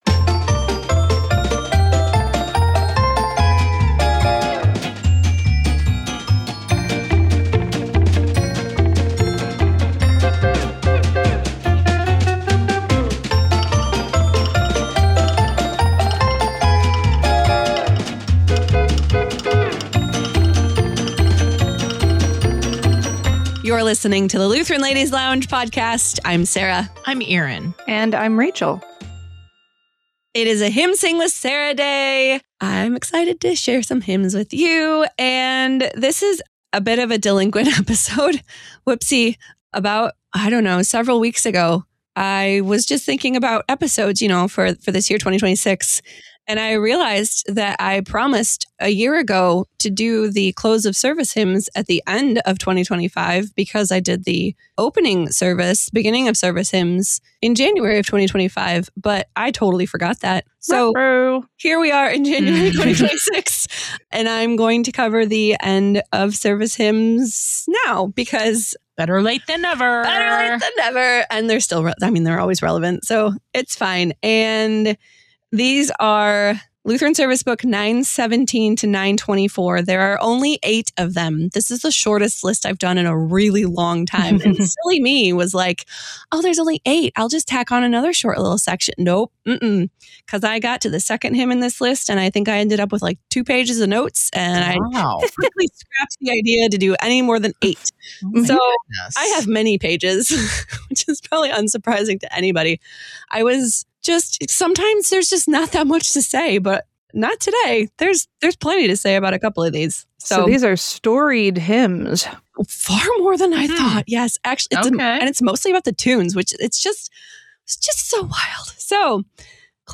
lead vocals
guitar